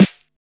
• Rich Low End Snare Drum Sound G Key 373.wav
Royality free acoustic snare sound tuned to the G note.
rich-low-end-snare-drum-sound-g-key-373-lp4.wav